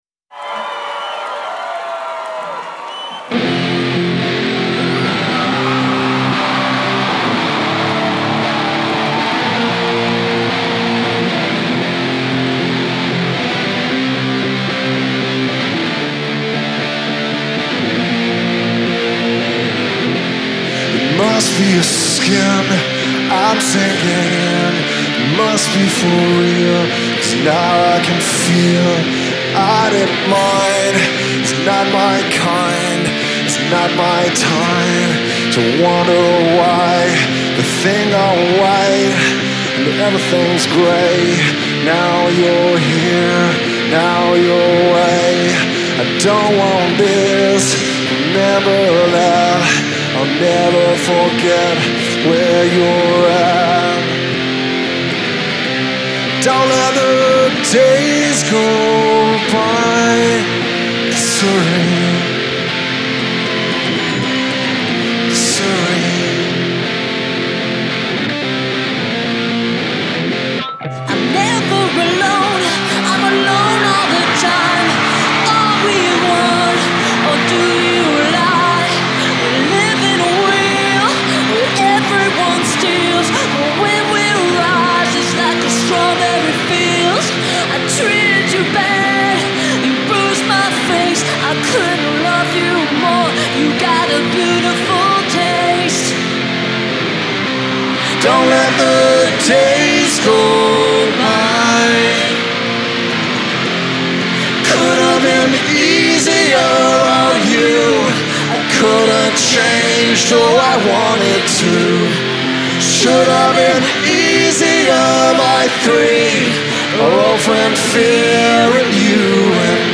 featuring a walk on  duet